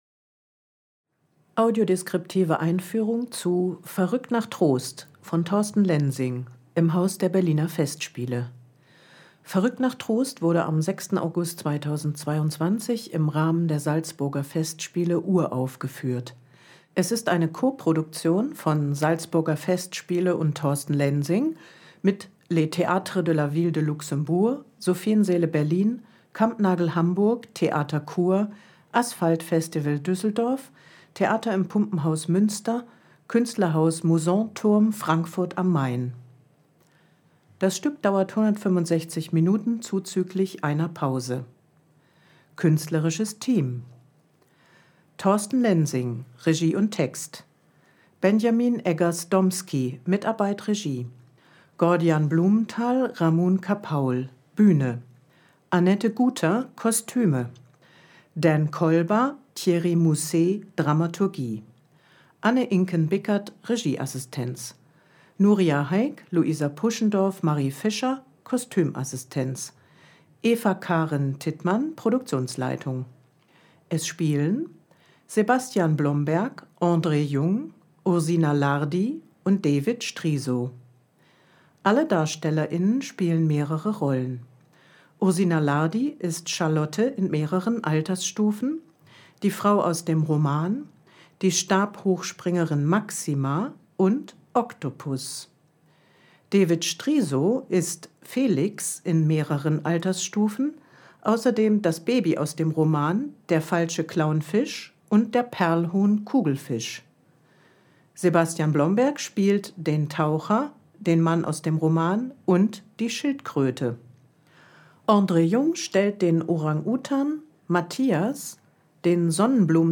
Audiodeskription „Verrückt nach Trost”